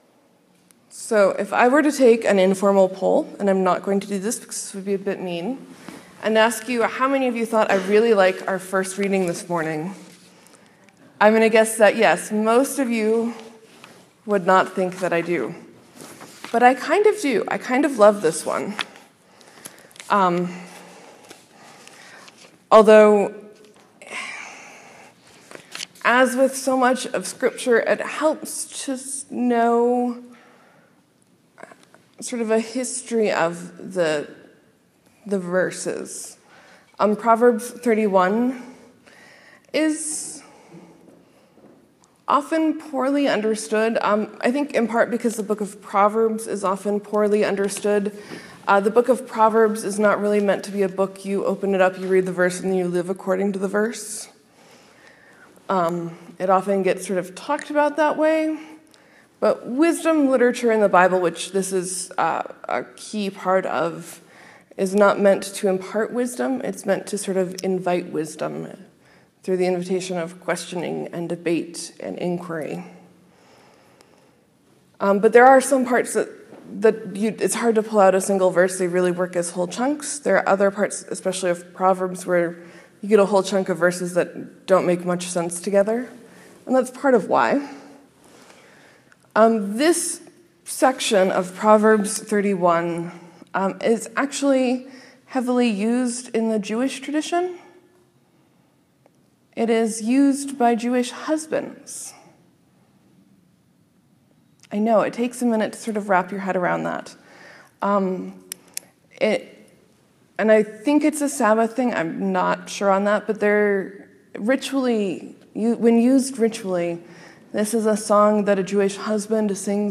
Sermon: Jesus talks about his death. So we do too because that is the great source of our hope.